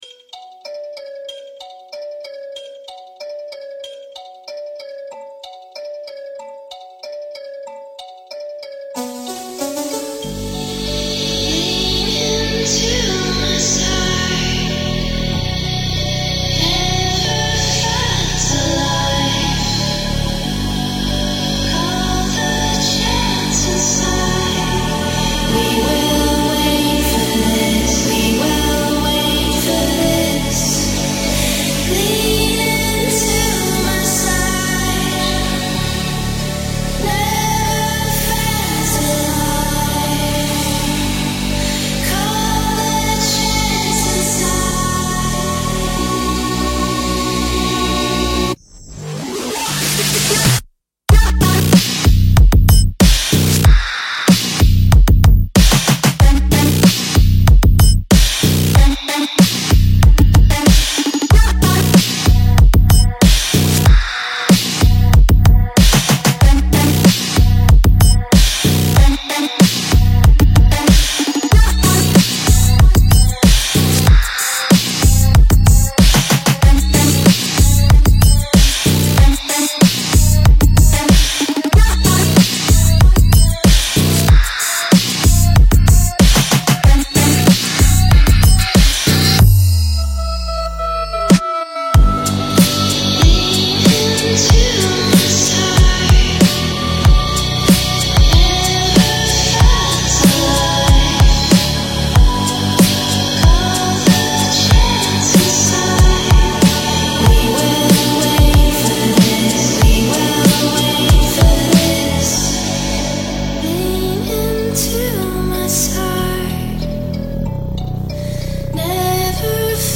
BPM94-94
Audio QualityPerfect (High Quality)
Midtempo Bass song for StepMania, ITGmania, Project Outfox
Full Length Song (not arcade length cut)